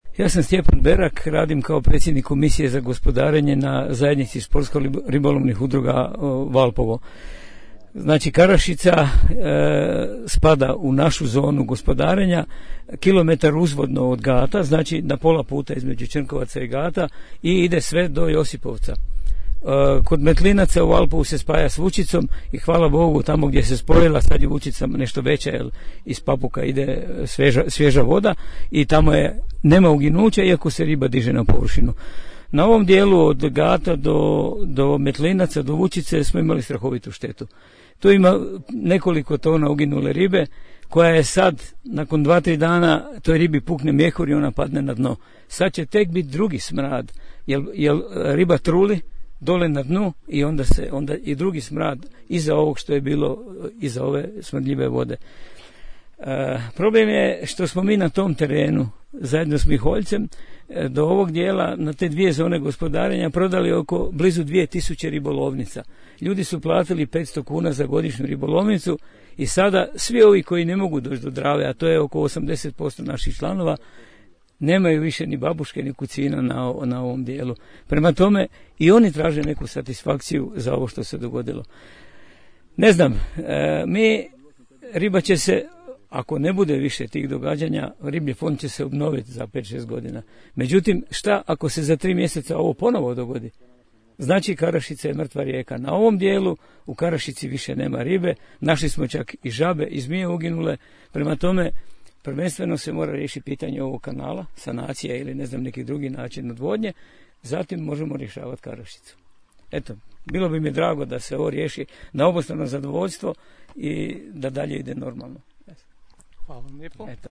Uz galeriju fotografija donosimo vam i tonske zapise sudionika prosvjeda.